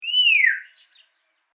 On my adventure to the local zoo I found out they had quite a few species of parrots.
Since parrots love to chatter, all I had to do was get close enough to get a good audio signal.
Parrots are generally quite loud.
free African grey parrot short sound
african_grey.mp3